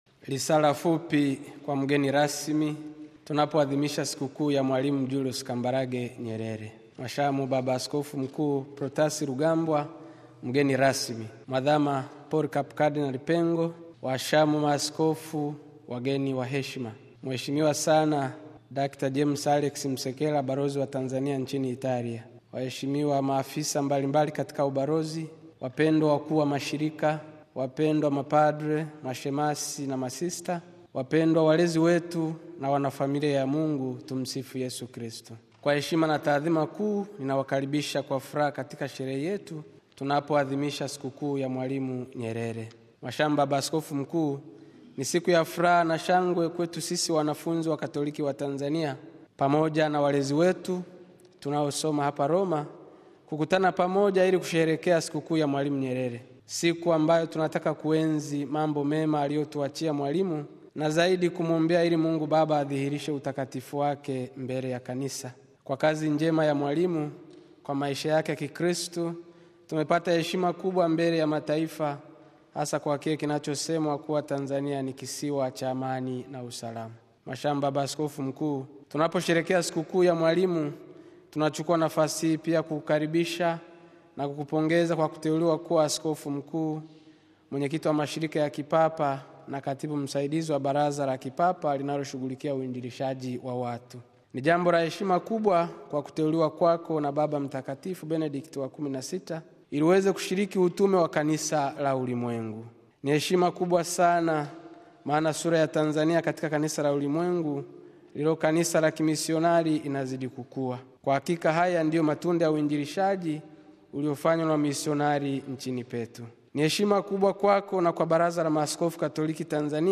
Hivi karibuni, Jumuiya ya Wanafunzi Wakatoliki Watanzania Wanaosoma Roma, iliadhimisha Ibada ya Misa Takatifu kwa ajili ya kumbu kumbu ya miaka kumi na mitatu tangu alipofariki dunia Mwalimu Julius Kambarage Nyerere, muasisi wa Taifa la Tanzania.
Ifuatayo ni risala fupi